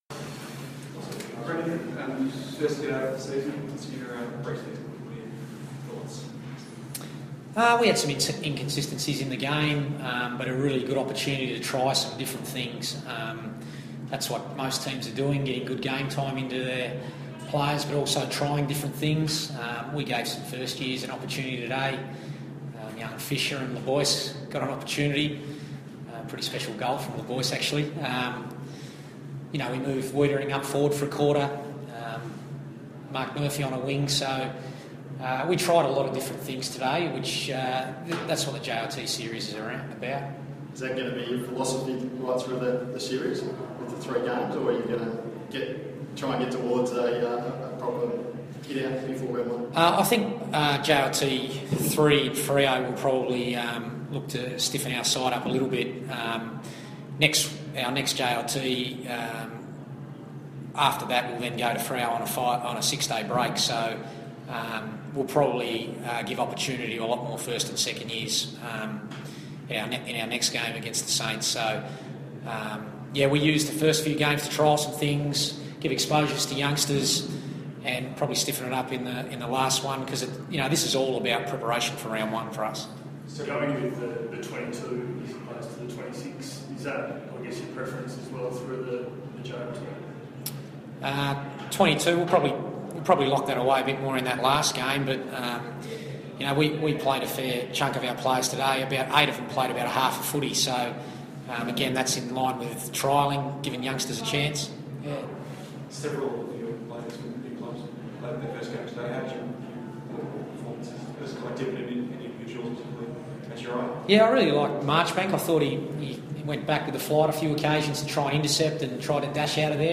post-match press conference